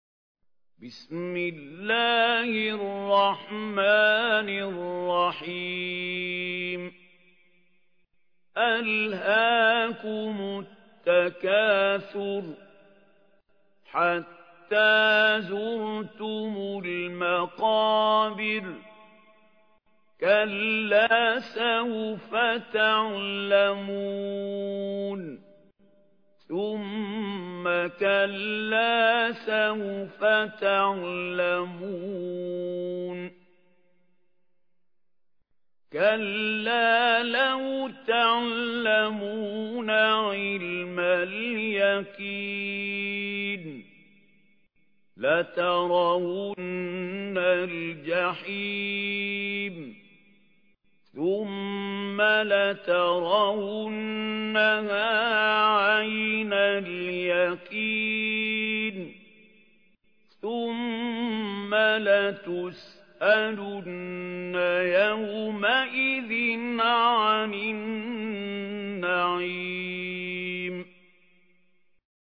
ترتيل